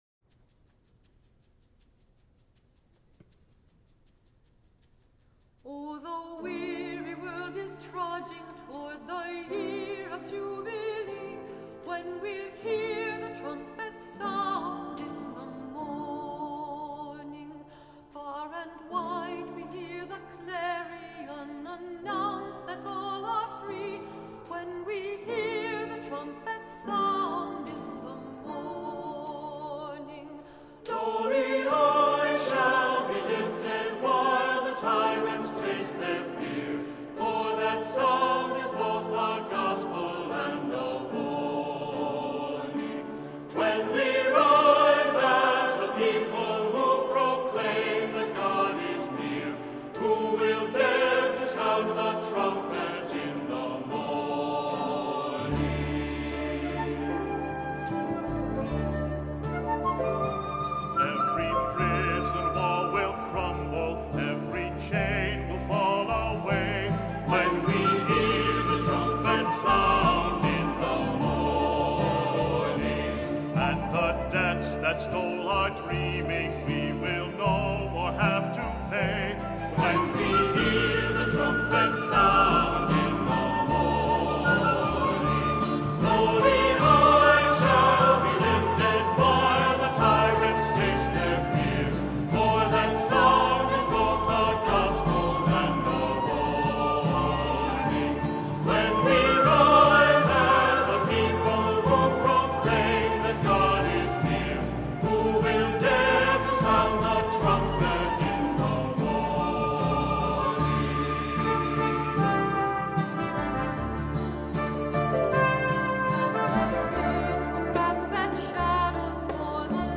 Accompaniment:      Keyboard
Music Category:      Christian
Instrument parts are optional. Piccolo or flute.